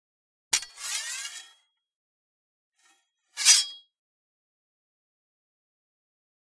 Sword: Remove From Sheath; Slow, Deliberate Metal Scrape As Sword Is Drawn Partially And Resheathed Quickly With Fast Scrape. Close Perspective.